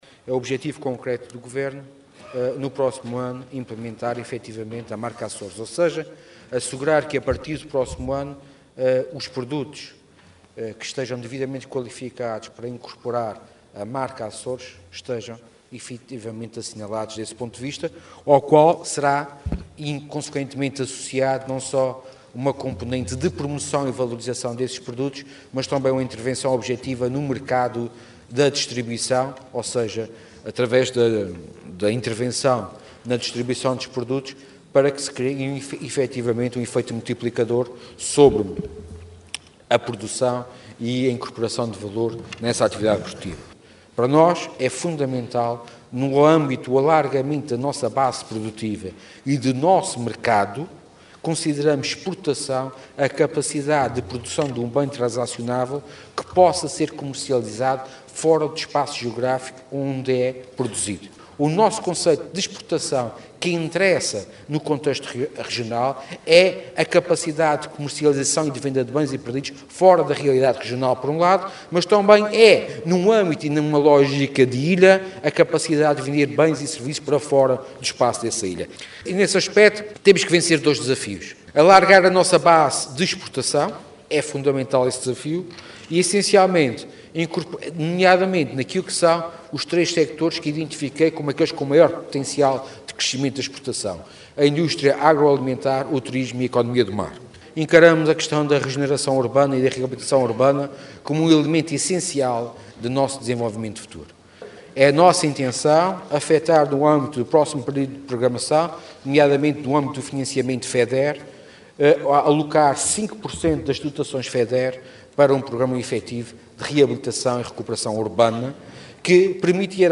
Sérgio Ávila, que respondia a perguntas no âmbito da discussão do Plano e Orçamento da Região para 2014, na Assembleia Legislativa, reiterou, por outro lado, a intenção do Governo de impulsionar a marca “Açores”, incorporando valor e promovendo, assim, “um efeito multiplicador” na atividade económica.